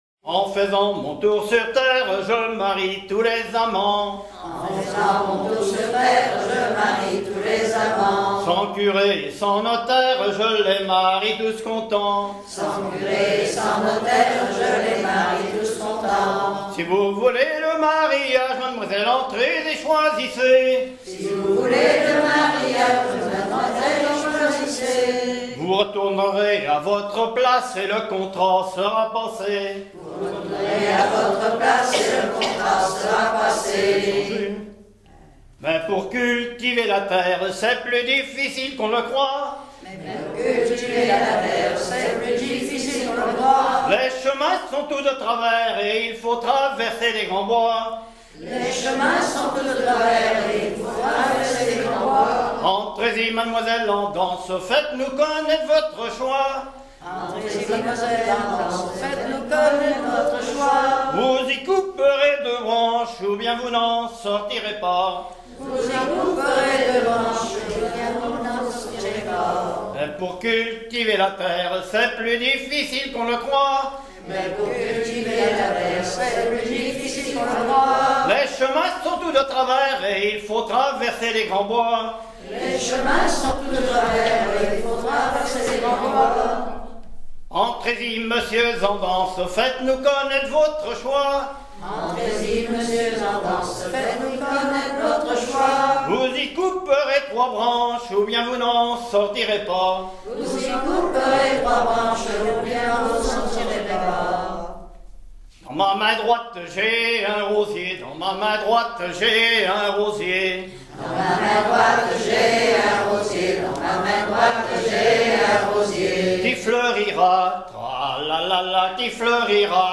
suite de cinq rondes à marier ou à embrasser
danse : ronde à marier